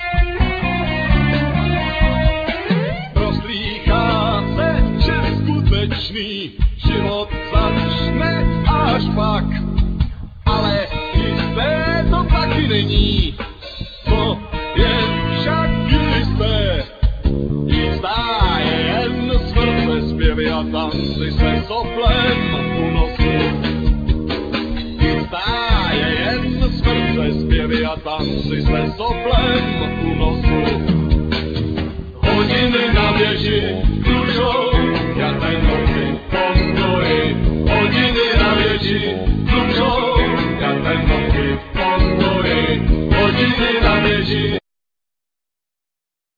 Vocals,Guitars,Drums programming,Saxophones
Drums
Bass,Opera Voice
Keyboards,Backing vocals
Sound effects,Noises